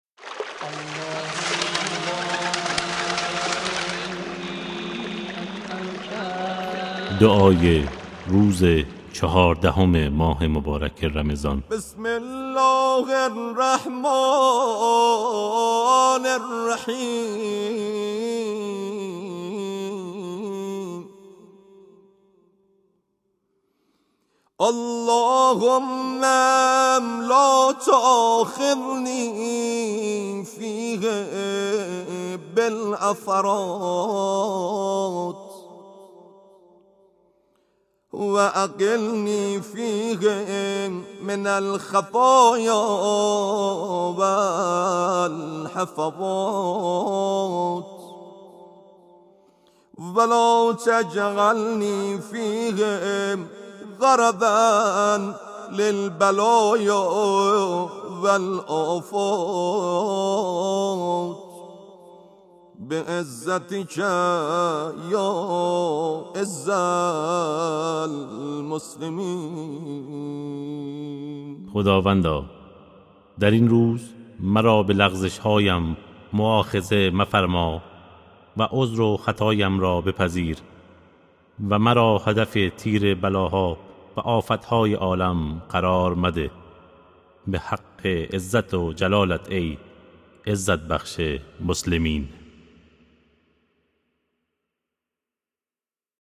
دعای روزهای ماه مبارک رمضان